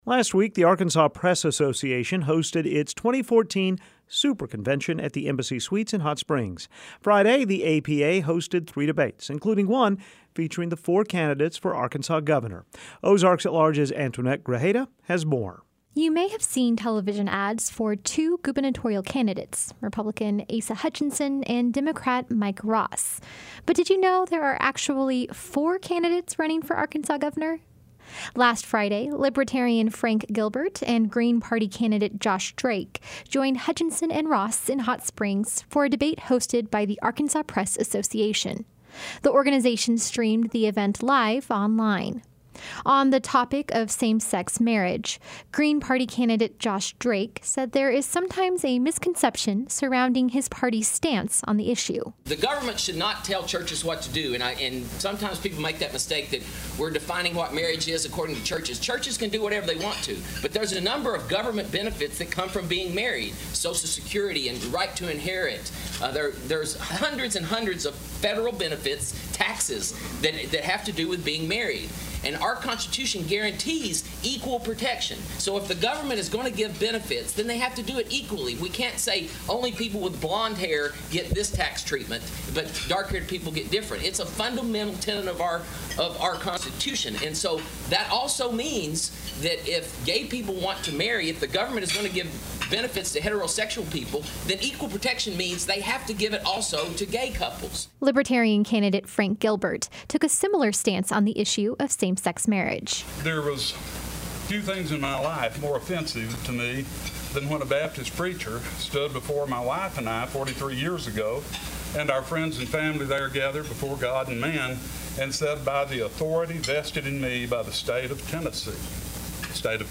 Gubernatorial Hopefuls Debate Same-Sex Marriage
The Arkansas Press Association hosted three debates last week, including one with the four candidates in this year's governor's race.